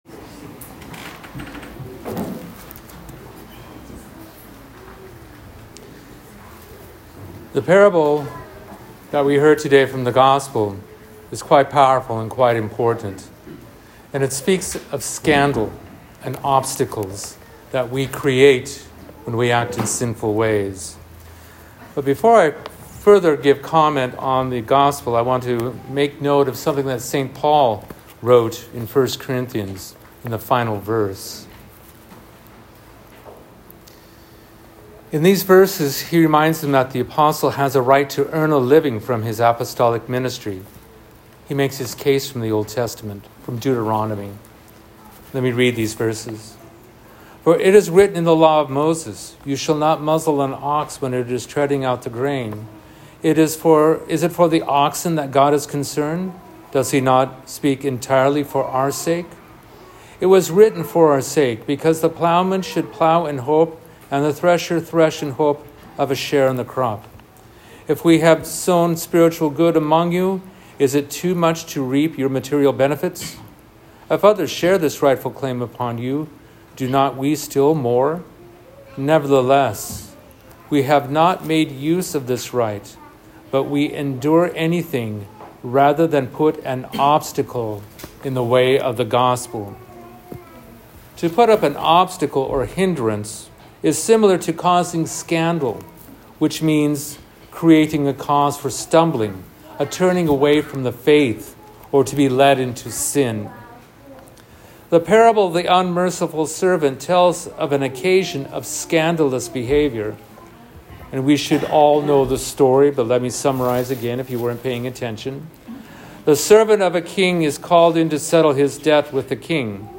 Presented here is the corresponding sermon given at Holy Resurrection Orthodox Church, Tacoma, Washington on August 24, 2025: https